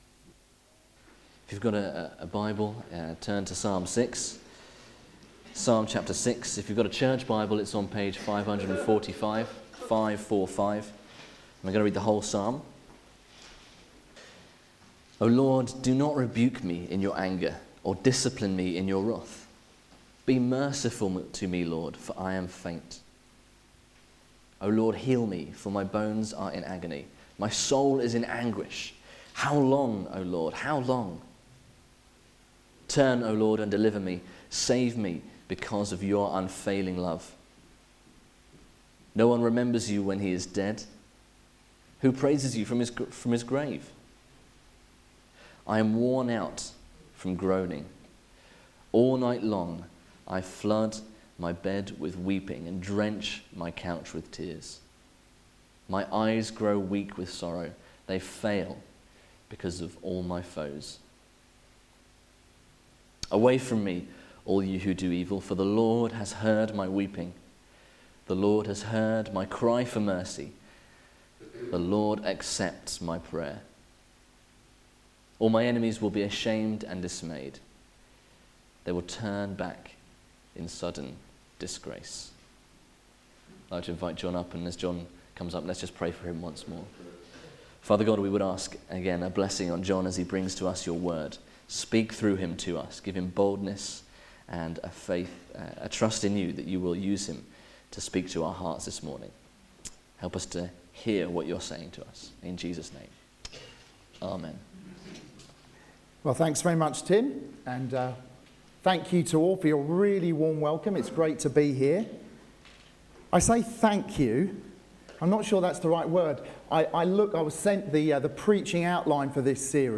Media for Sunday Service
Series: Prayer Series Theme: Unanswered Prayer Sermon